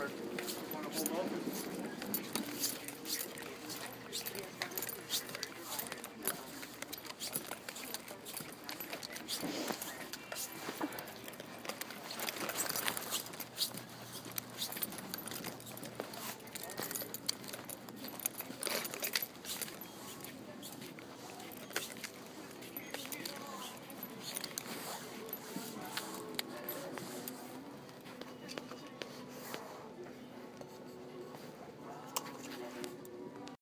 Field Recording #2
Location: CVS Hempstead Turnpike
Sounds heard: customers and employees talking, jacket brushing against itself, foot steps, keys jangling, music from the store